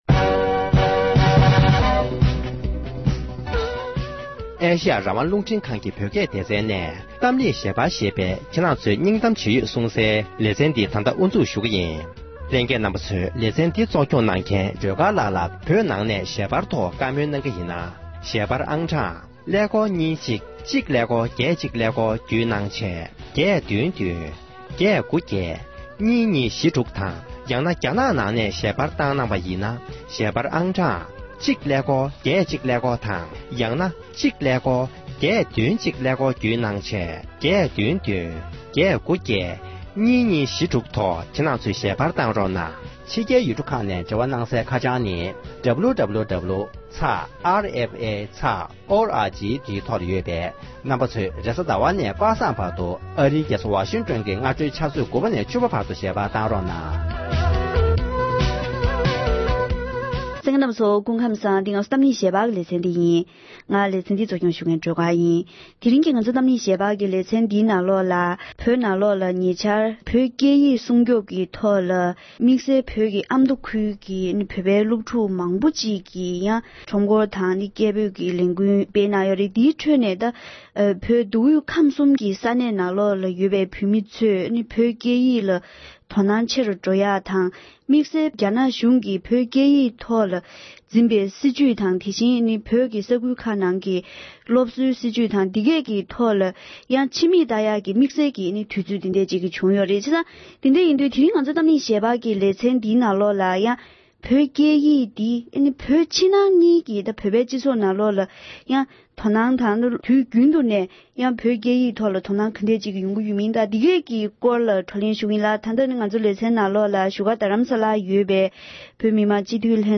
བོད་ཨ་མདོ་ཁུལ་གྱི་བོད་པའི་སློབ་མ་ཚོས་བོད་ཀྱི་སྐད་ཡིག་ལ་སྲུང་སྐྱོབ་ཀྱི་ལས་འགུལ་སྤེལ་བའི་སྐོར་བགྲོ་གླེང༌།